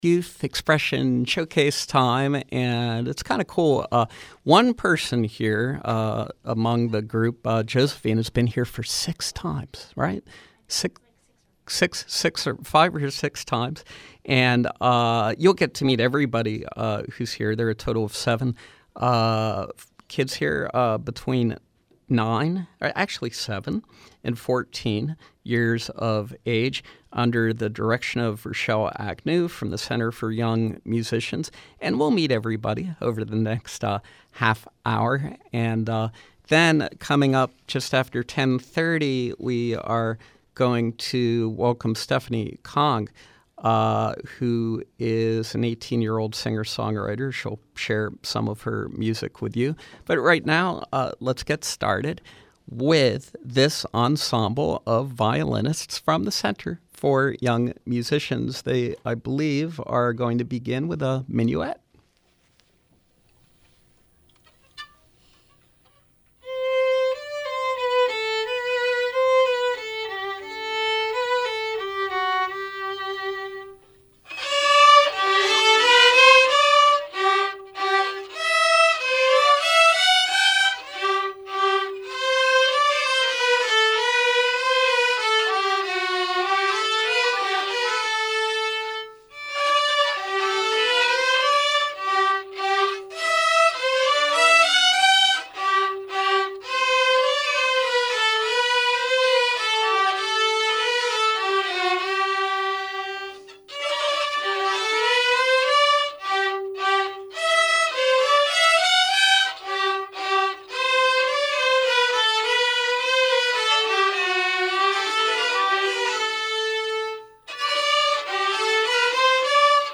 Violinists from the Center for Young Musicians
They played Minuet 3, Musette, Bourree , Etude, and Allegro on Violin.